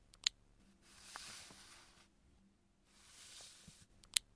Открытие и сворачивание окна на компьютере
otkritie_i_svorachivanie_okna_na_kompyutere_bm5.mp3